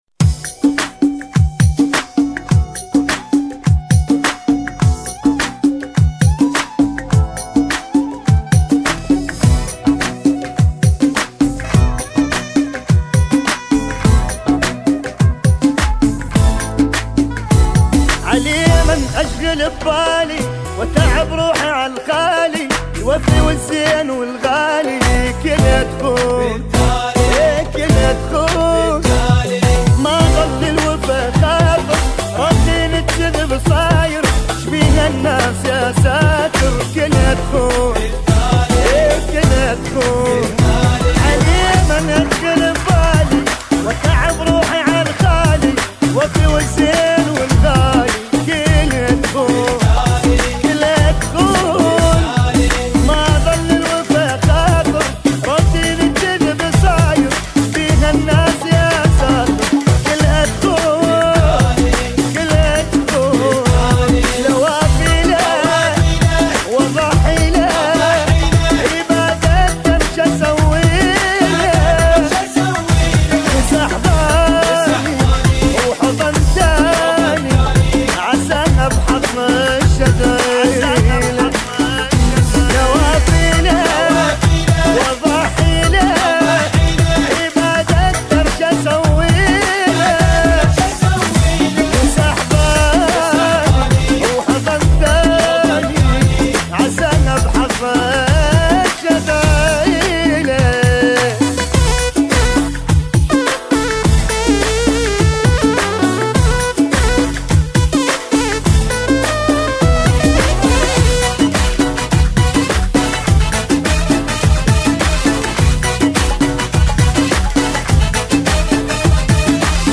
110 bpm